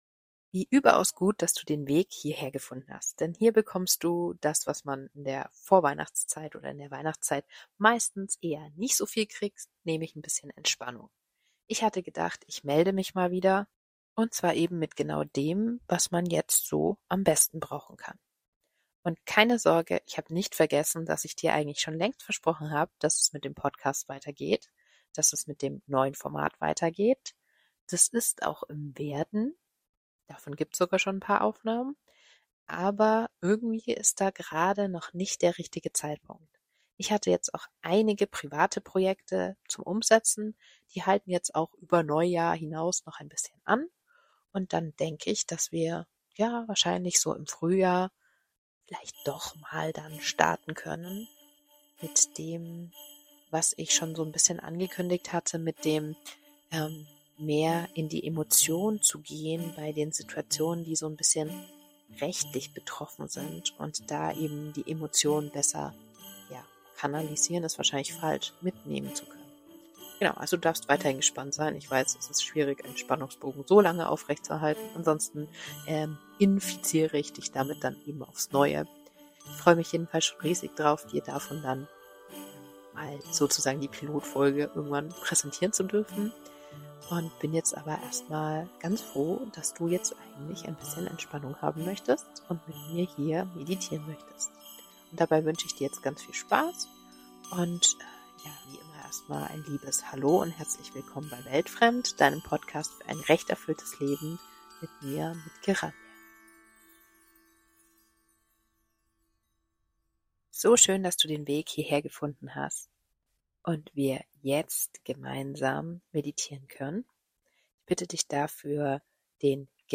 Viel Freude mit dieser geführten, vorweihnachtlichen Meditation für ein bisschen mehr Zurück zum Wesentlichen.